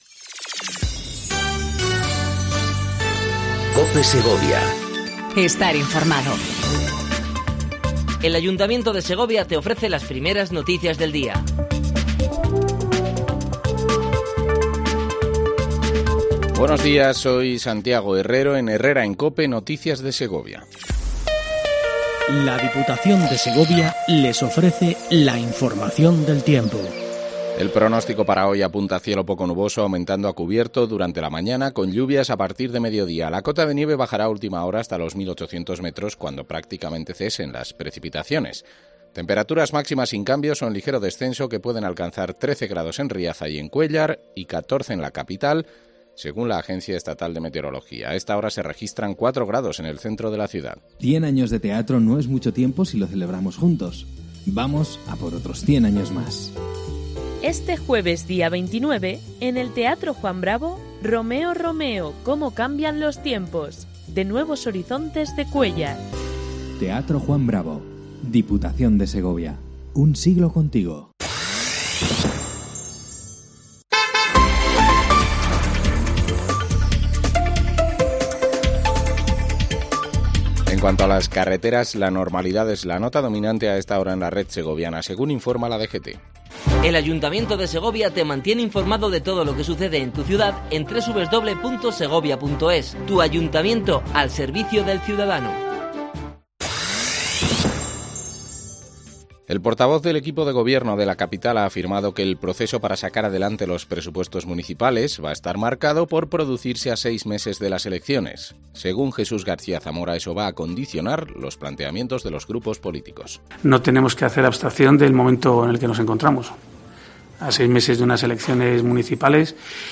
INFORMATIVO 07:55 COPE SEGOVIA 29/11/18
AUDIO: Primer informativo local en cope segovia